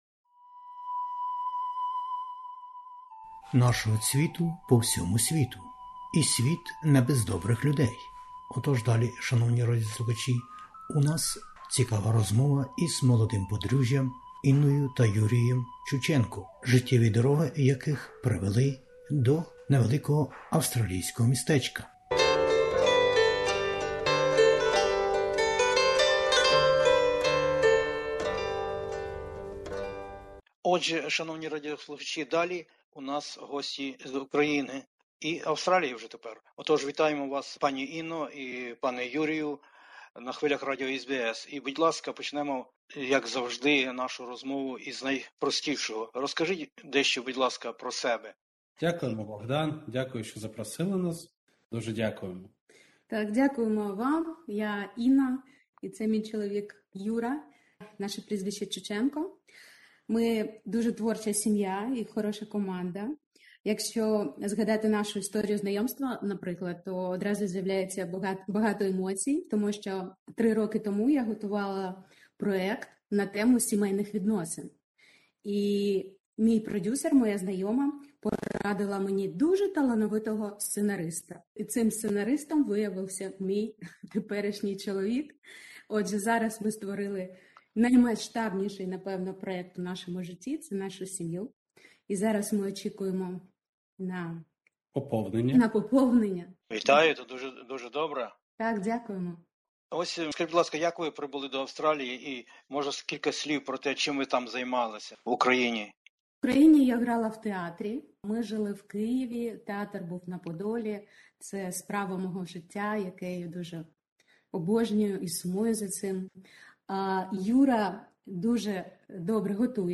розмовляє із молодим подружжям із України